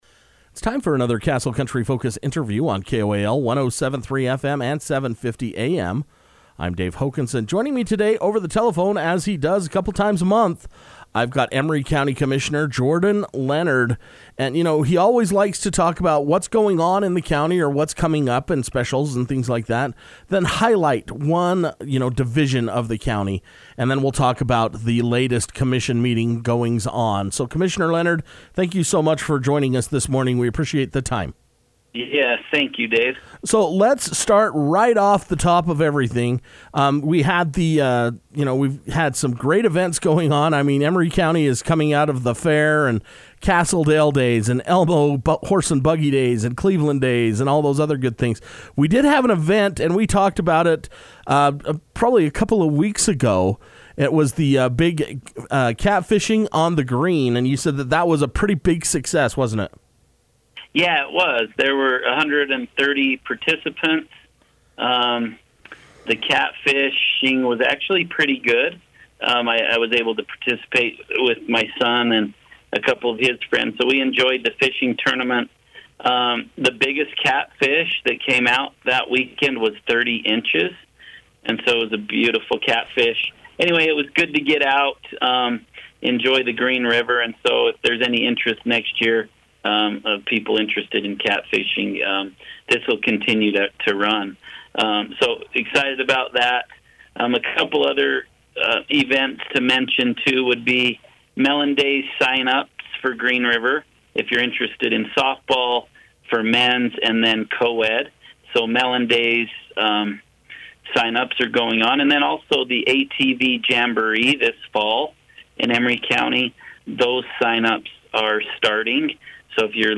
It’s time to sit down with Emery County Commissioner Jordan Leonard to find out about the Catfish on the Green event, recognize the San Rafael Museum and announce the newly appointed board members. Castle Country Radio will now have an opportunity every other week to speak with the commissioner to keep citizens informed on the latest news and events.